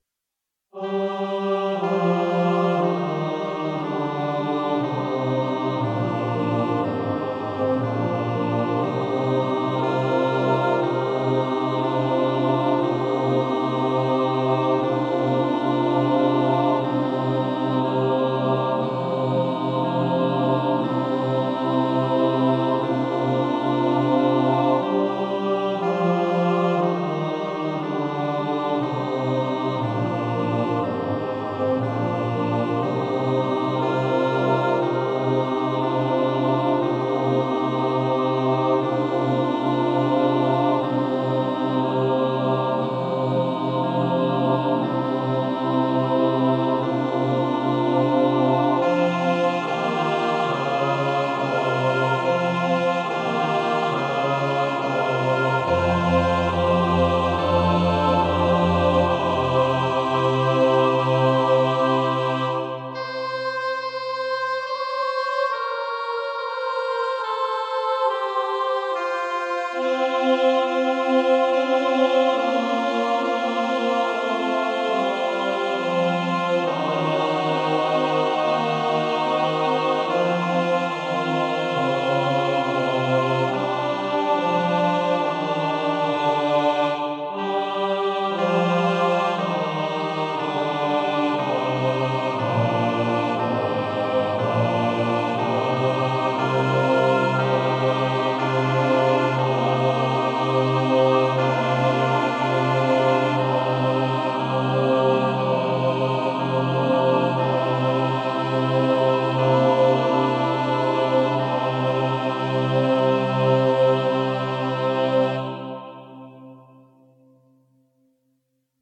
SATB
pro smíšený sbor